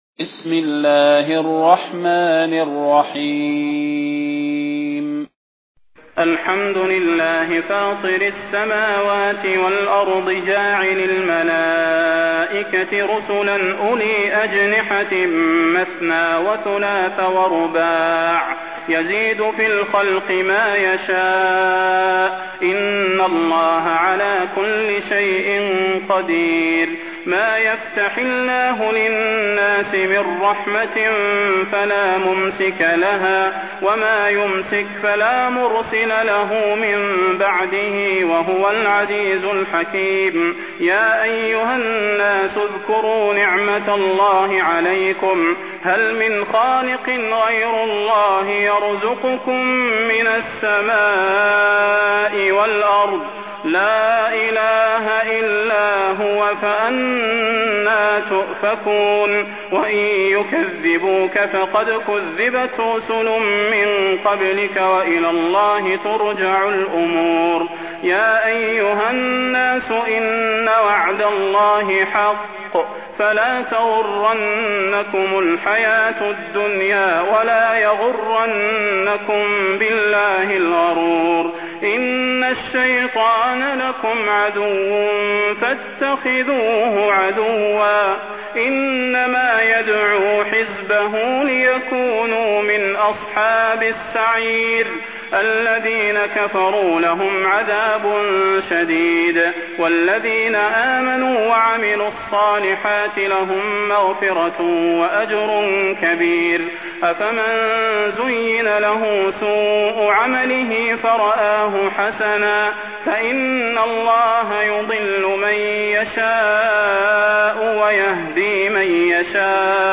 المكان: المسجد النبوي الشيخ: فضيلة الشيخ د. صلاح بن محمد البدير فضيلة الشيخ د. صلاح بن محمد البدير فاطر